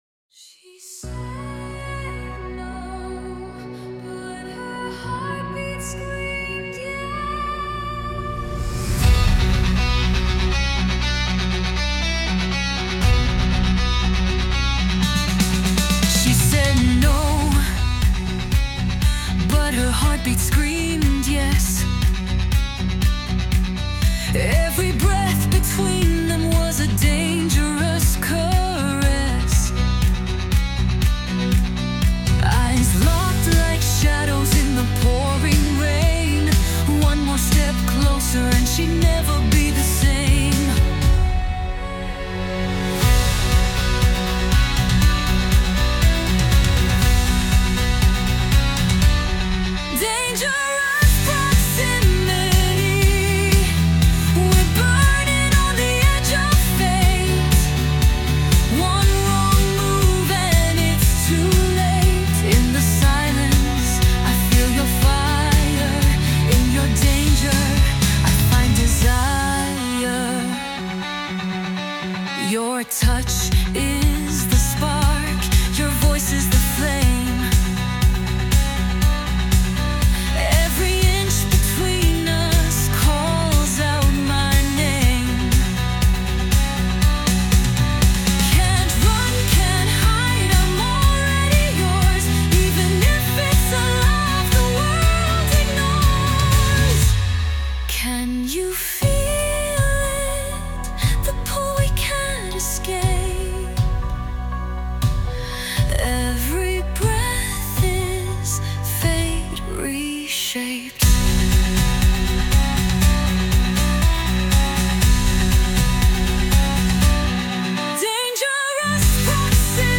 Audiobooks
Feel the kilig through voice and music.